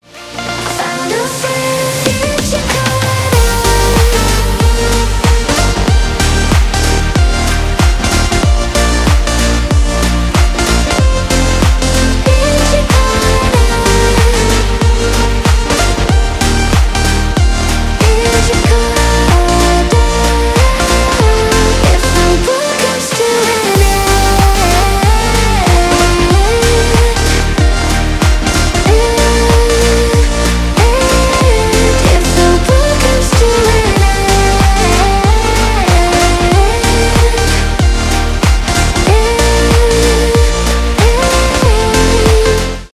танцевальные
позитивные , electronic